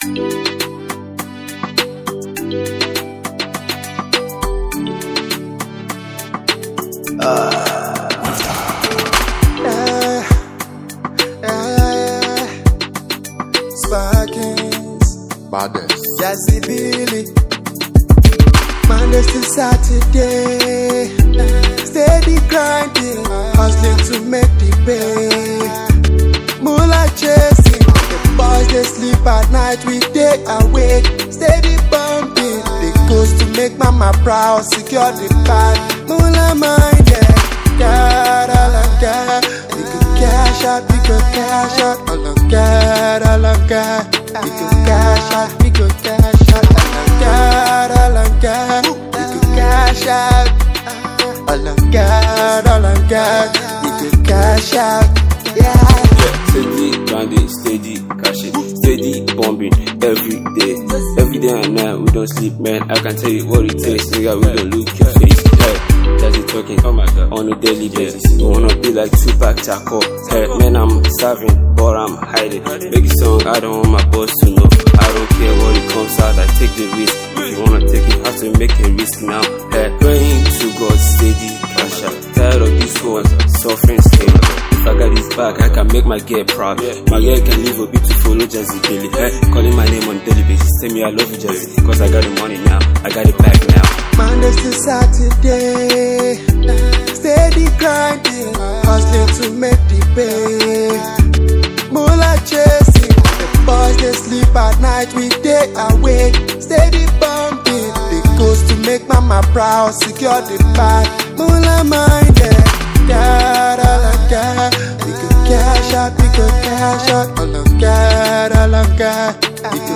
Afro song